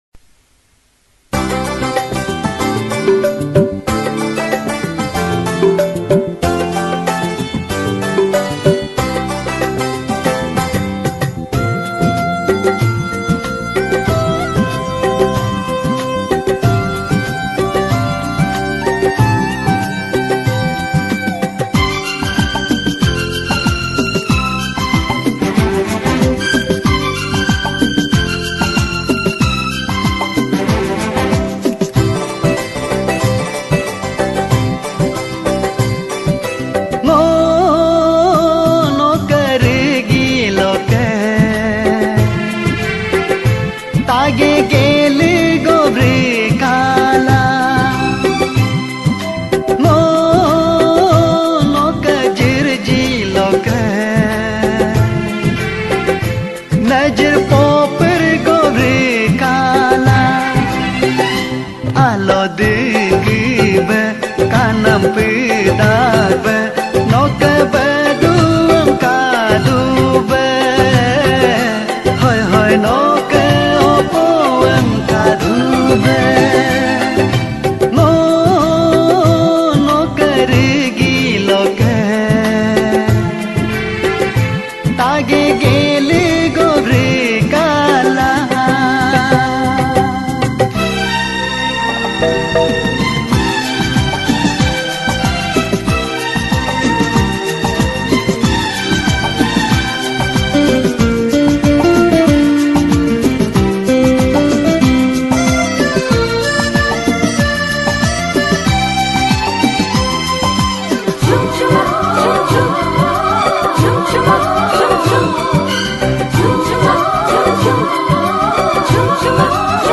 Galo Folk Song